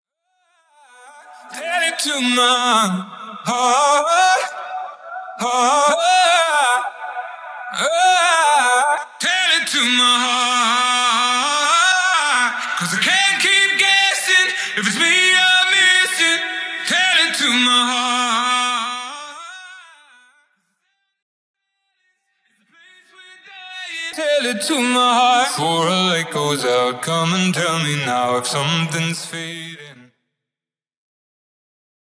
Listen to the demo acapella on our official channel :
LATEST UPDATE ON VOCAL VERSION :